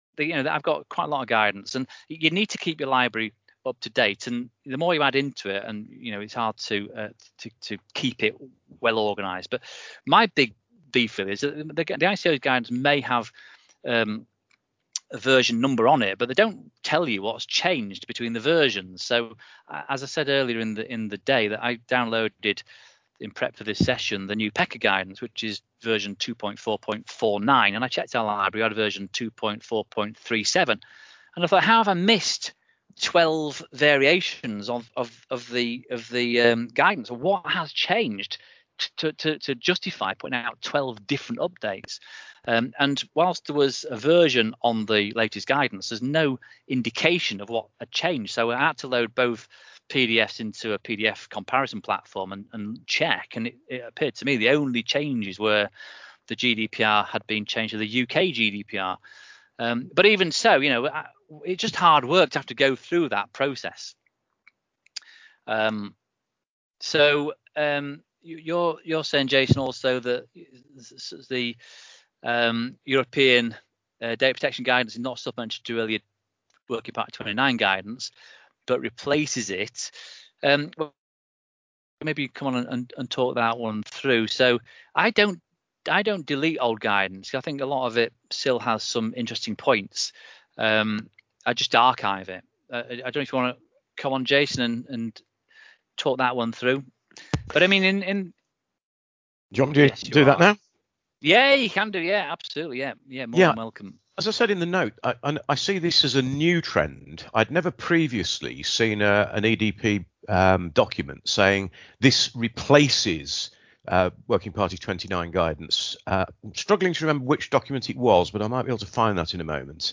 For Data Protection Day we ran a 6-hour long webinar where we spoke about various different topics and attempted to provide free support to small businesses.